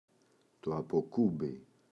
αποκούμπι, το [apo’kumbi]